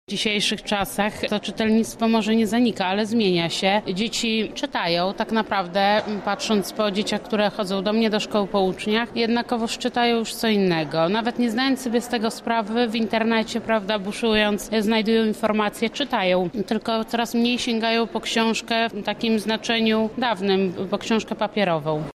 Dziś odbyła się konferencja „Upowszechnianie czytelnictwa i rozwój kompetencji czytelniczych”.